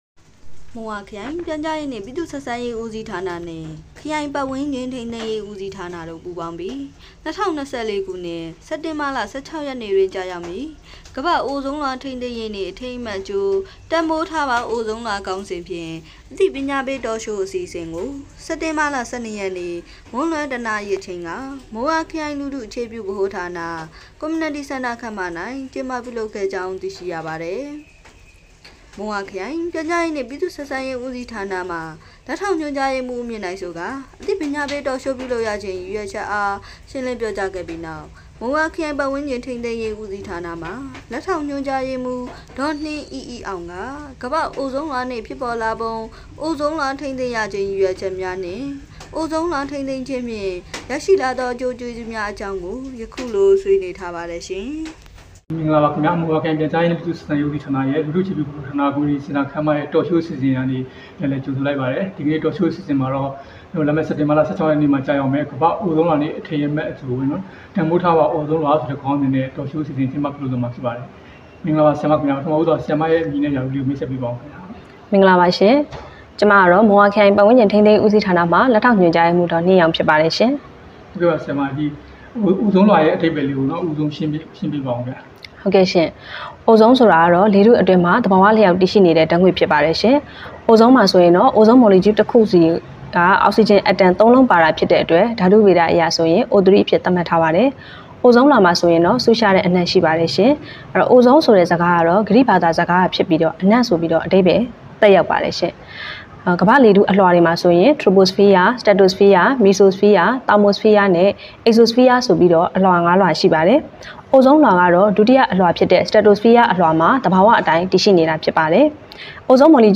မုံရွာမြို့တွင် ကမ္ဘာ့ အိုဇုန်းလွာ ထိန်းသိမ်းရေနေ့အထိမ်းအမှတ် တန်ဖိုးထားပါ အိုဇုန်း လွှာ ခေါင်းစဉ်ဖြင့် အသိပညာပေး Talk Show အစီအစဉ် ရိုက်ကူးတင်ဆက် မုံရွာ၊ စက်တင်ဘာ ၁၂ သတင်းပေးပို့သူ= မုံရွာခရိုင်ပြန်/ဆက်